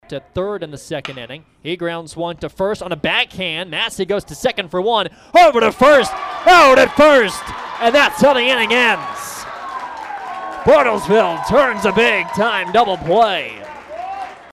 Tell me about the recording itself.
Here is how the final call sounded on KWON.